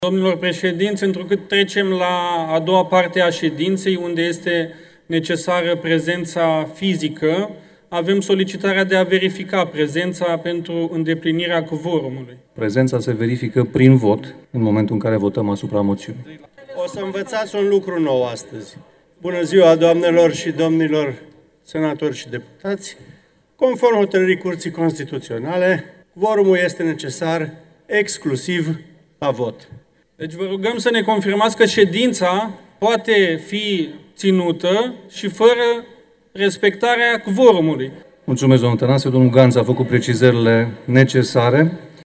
O nouă încercare pentru stabilirea cvorumului a fost făcută și în plenul reunit. Deputatul AUR Mihai Enache a cerut să se facă din nou prezența.